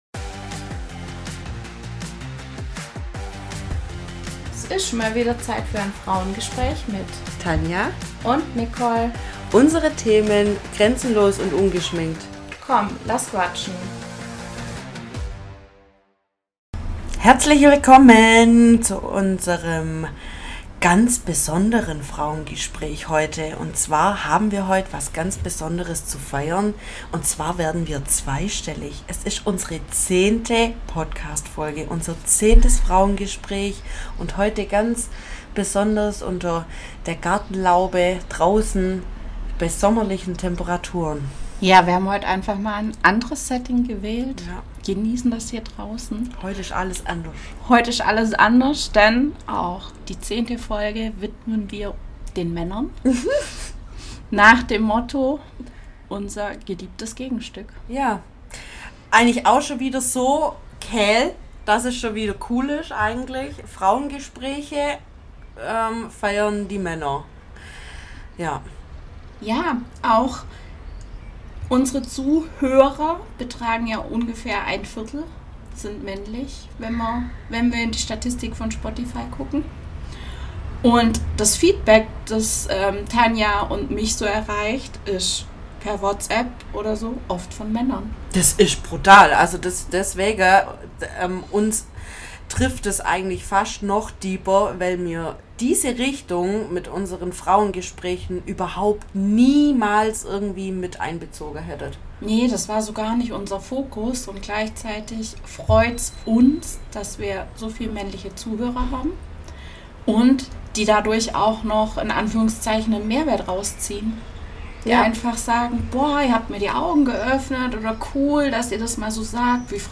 #010 Männer - unser geliebtes Gegenstück ~ Frauengespräche │ grenzenlos & ungeschminkt Podcast